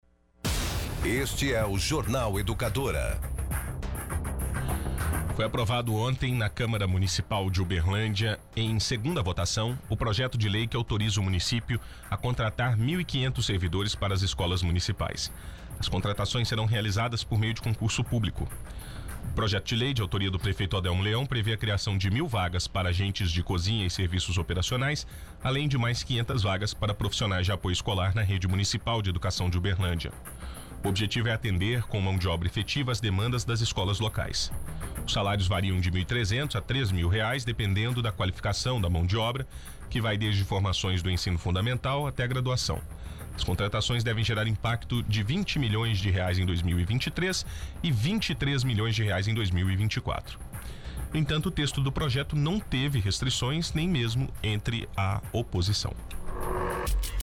Rádio